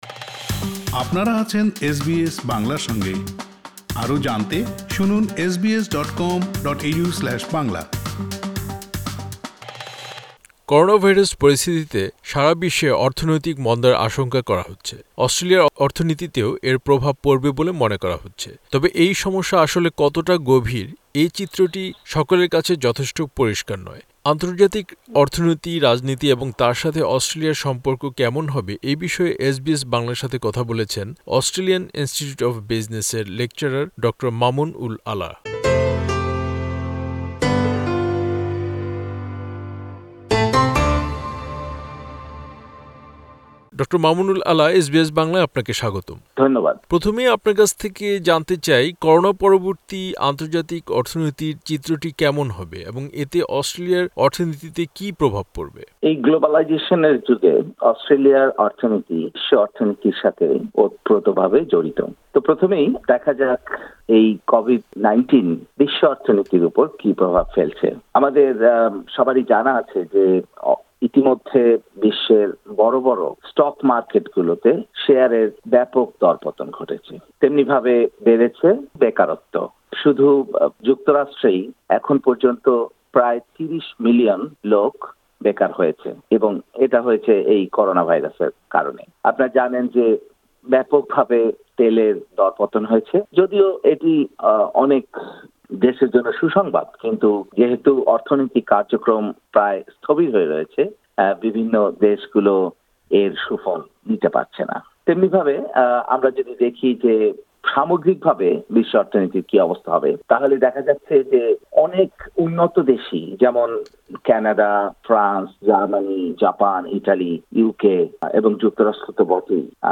Source: SBS এসবিএস বাংলা